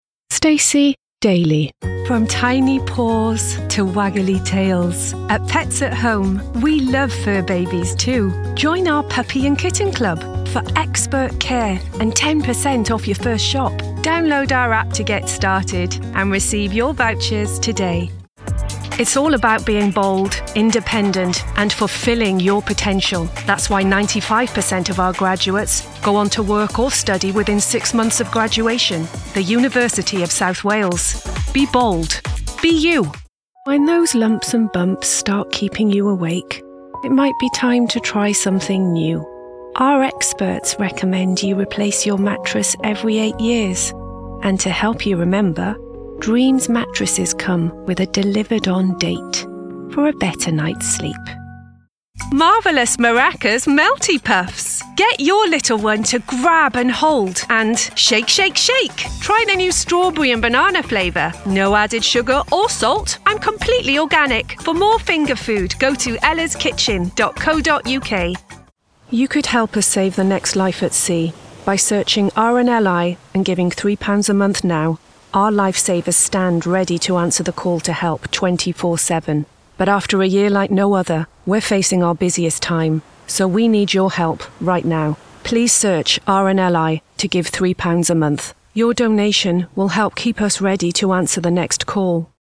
Showreel
Female / 30s, 40s / English / Welsh